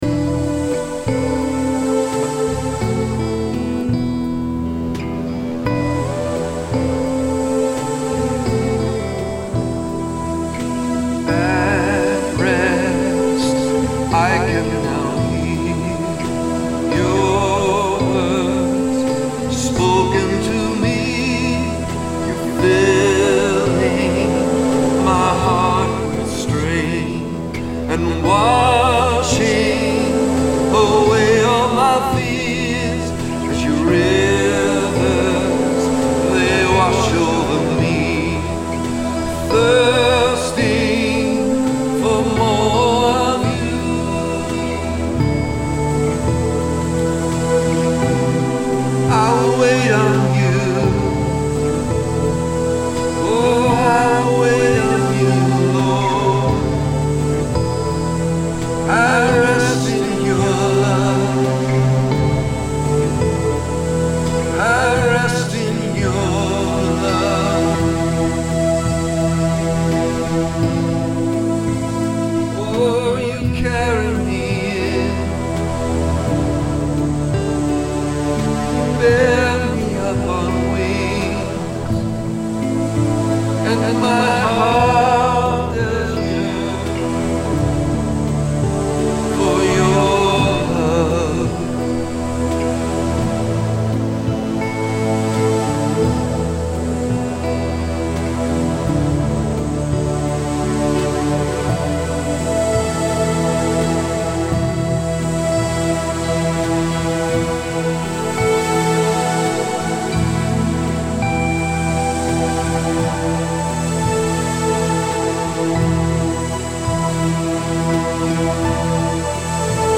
He wrote this song that was captured at one of the prayer meetings.